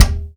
• Sharp Tome Drum Sample D# Key 3.wav
Royality free tome drum sample tuned to the D# note. Loudest frequency: 1250Hz
sharp-tome-drum-sample-d-sharp-key-3-nIR.wav